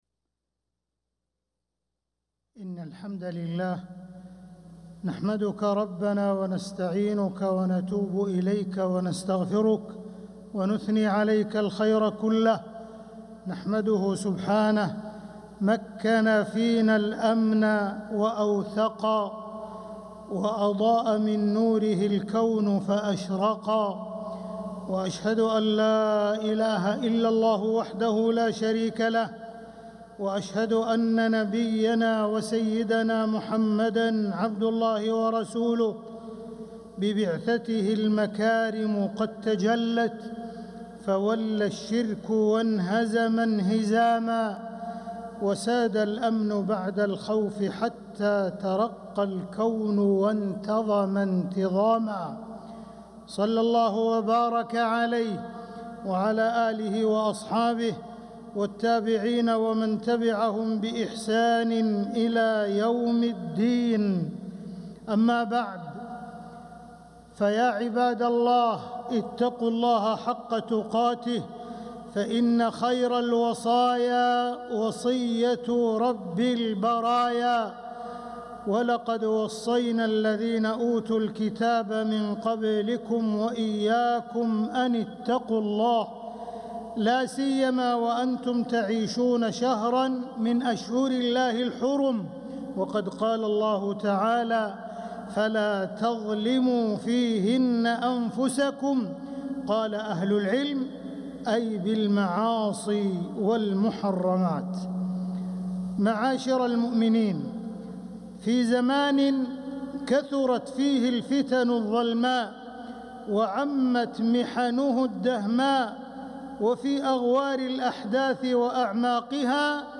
خطبة الجمعة 11 ذو القعدة 1446هـ | Khutbah Jumu’ah 9-5-2025 > خطب الحرم المكي عام 1446 🕋 > خطب الحرم المكي 🕋 > المزيد - تلاوات الحرمين